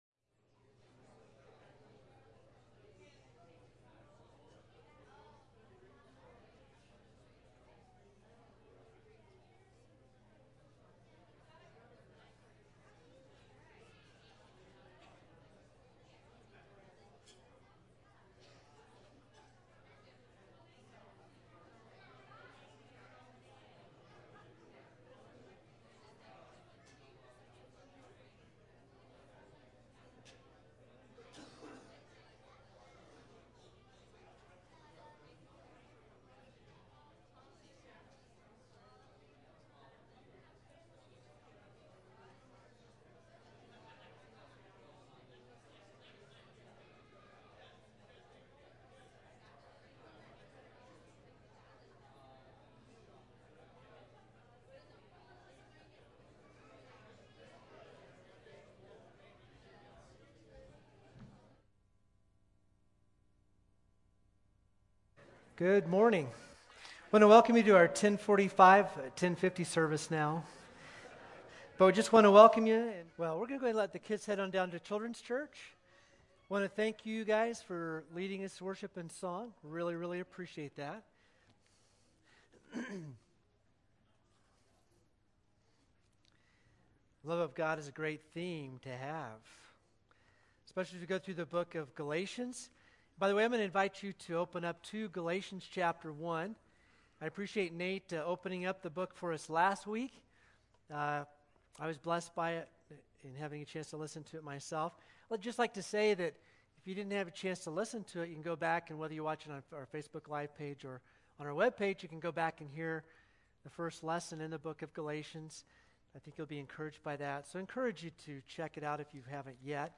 6_27-21-sermon.mp3